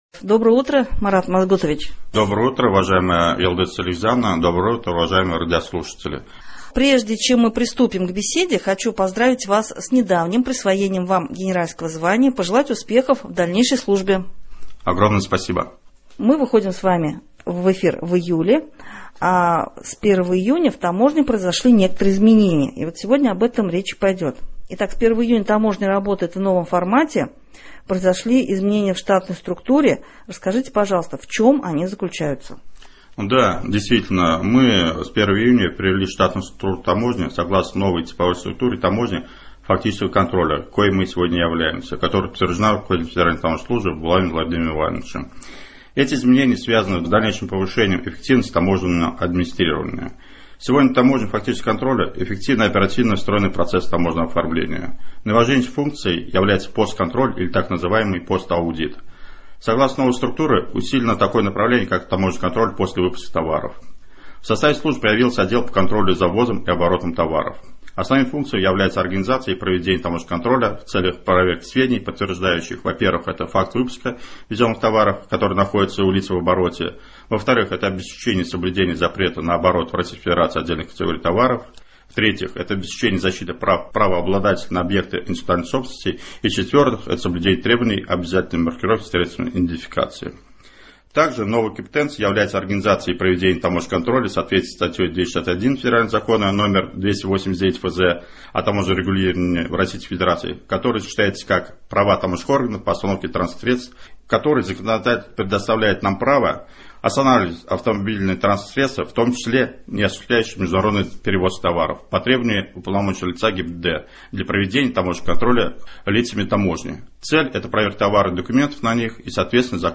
О изменениях в работе Татарстанской таможни расскажет начальник таможни Марат Гараев. Беседу вела журналист